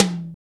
TOM12.wav